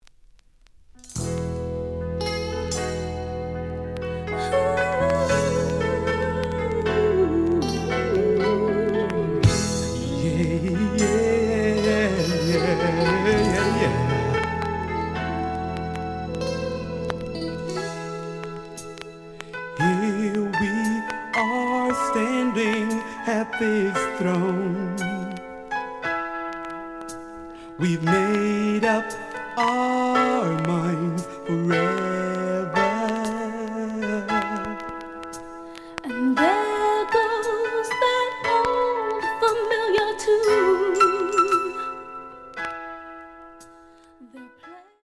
The audio sample is recorded from the actual item.
●Genre: Soul, 80's / 90's Soul
Some click noise on A side due to scratches.